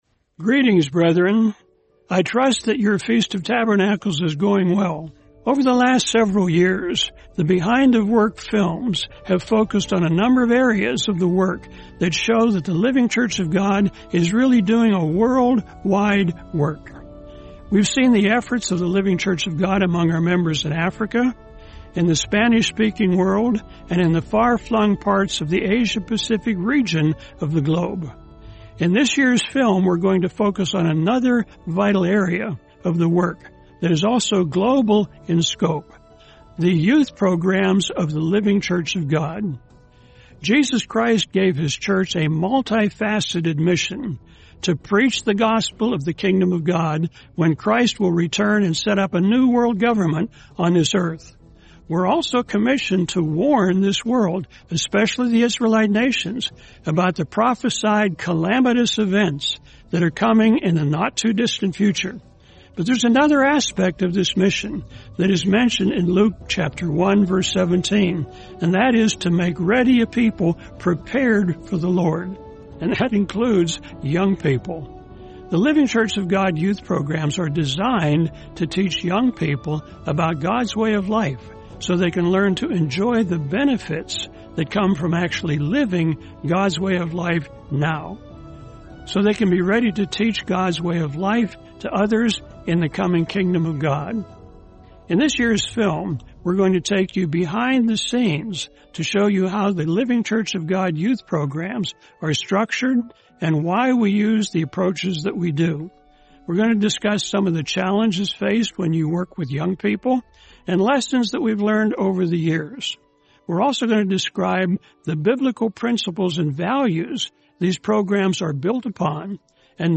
Behind the Work 2025: Preparing the Youth | Sermon | LCG Members